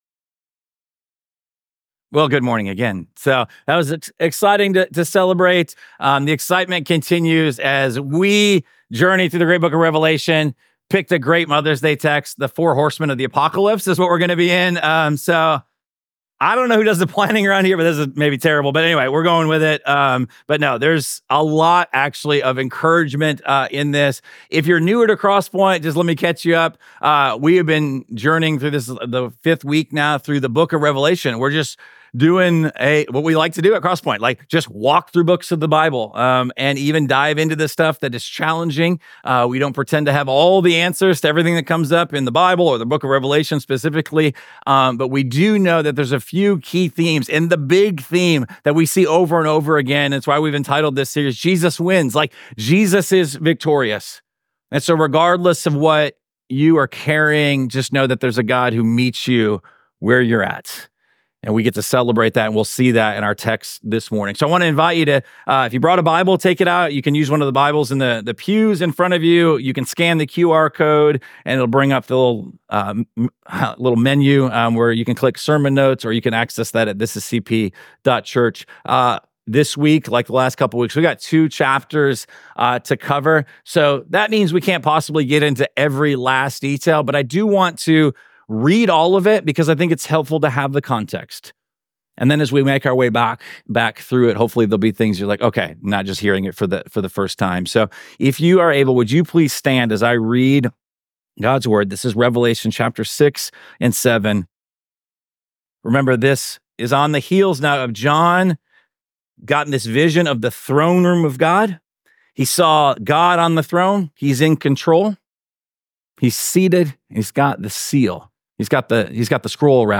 Week 5 of our series Jesus Wins: A Study of Revelation. This sermon comes from Revelation chapters 6-7.